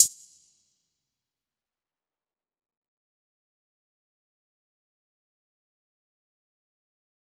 DMV3_Hi Hat 6.wav